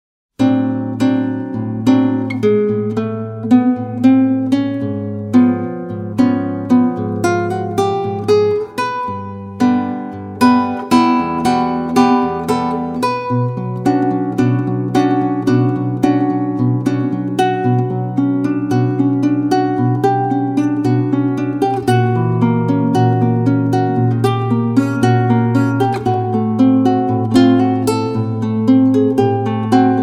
Guitar
Drums and Percussions